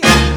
JAZZ HIT.wav